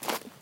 step2.wav